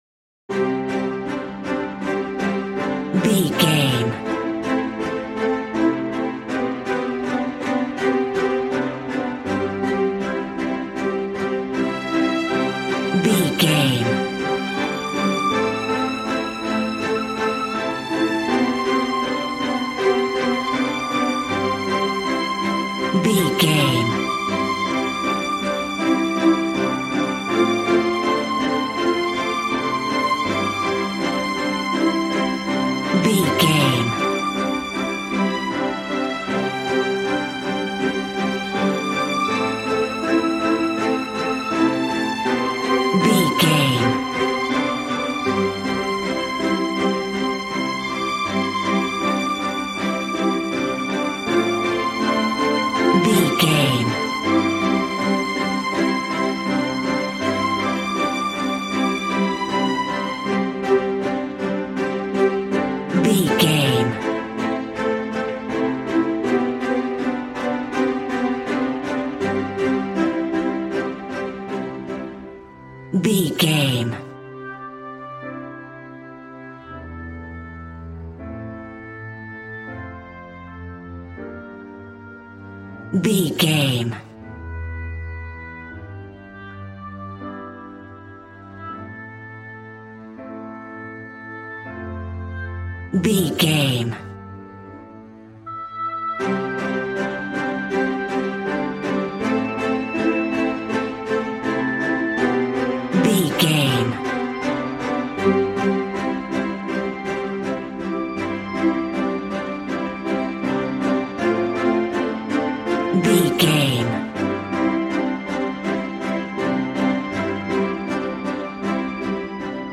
Regal and romantic, a classy piece of classical music.
Ionian/Major
cello
violin
strings